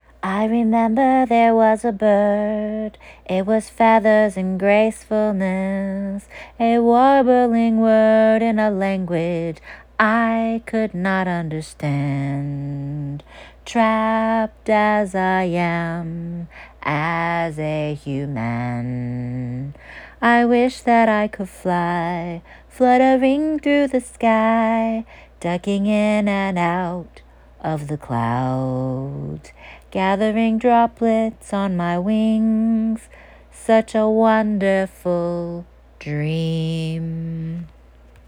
AUDIO: singing poetry
I know I’m pitchy.
Fantasy-talky.m4a